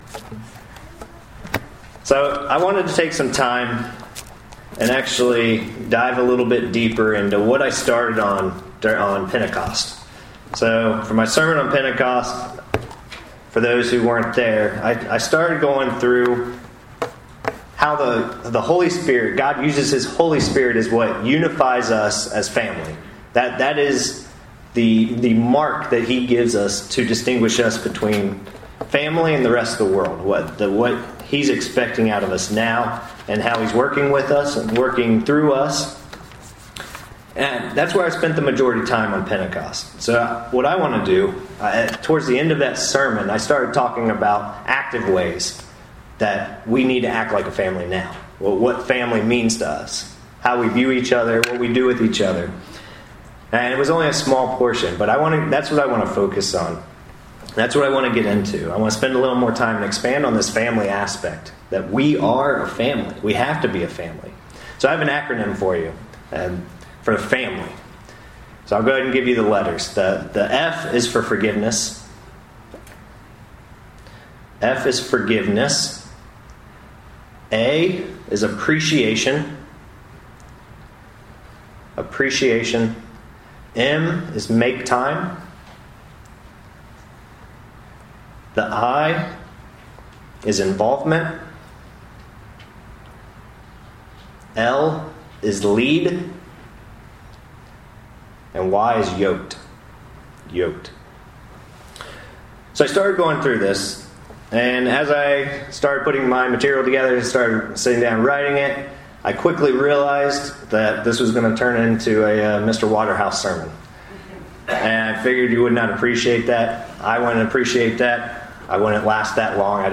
The Holy Spirit has been given to us to unify us as a family. This sermon is part 1 of 2 sermons about this topic. FAMILY = Forgiveness; Appreciation; Make time; Involvement; Lead; Yoked.